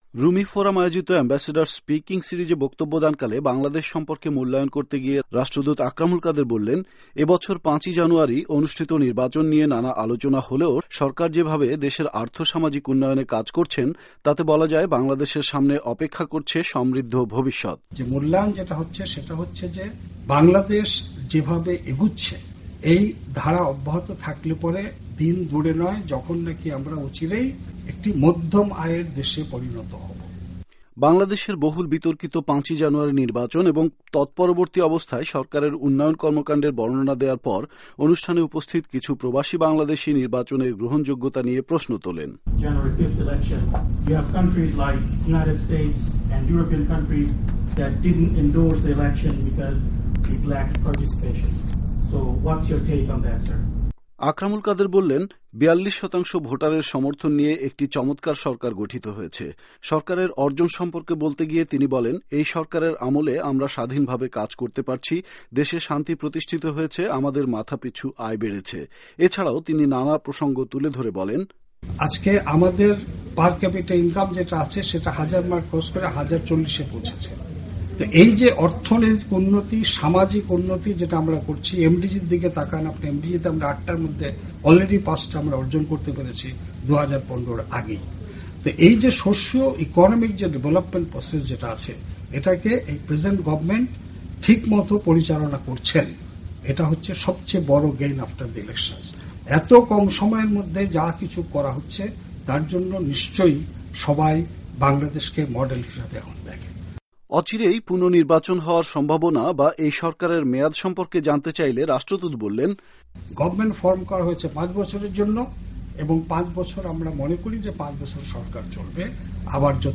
ওয়াশিংটনের থিংক ট্যাংক সংগঠন ‘রুমি ফোরামের’ উদ্যোগে ‘বাংলাদেশের আর্থ-সামাজিক উন্নয়ন এবং নির্বাচন পরবর্তী অবস্থা’ শীর্ষক এ্যাম্বাসেডরস স্পিকিং সিরিজে বক্তব্য রাখেন বাংলাদেশের রাষ্ট্রদূত আকরামুল কাদের।